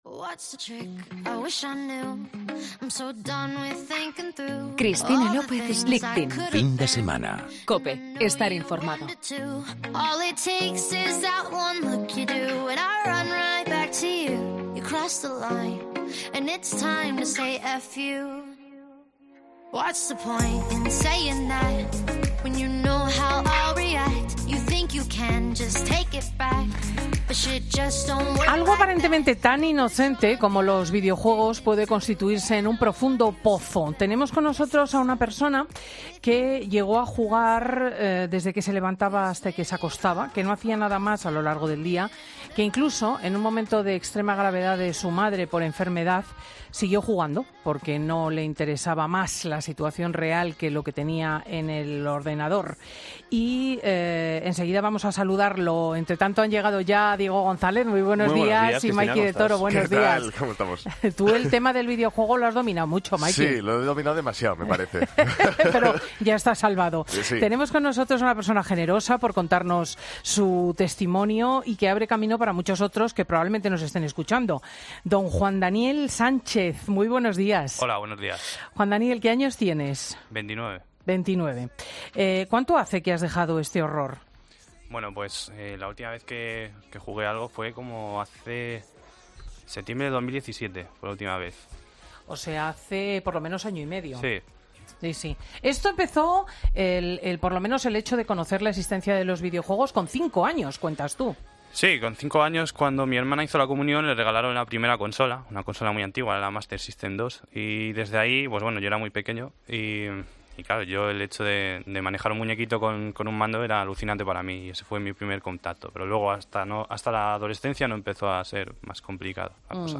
Ha contado su testimonio a Cristina López Schlichting.